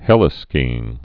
(hĕlĭ-skēĭng)